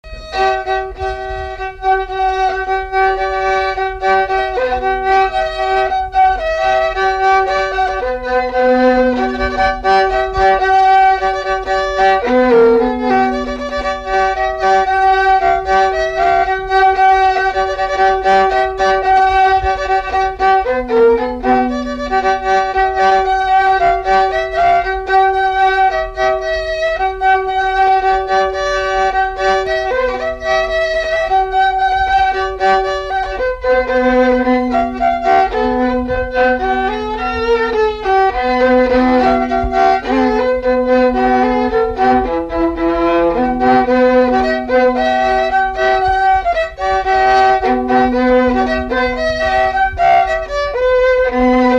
Polka
Chants brefs - A danser
Résumé instrumental
danse : polka
Pièce musicale inédite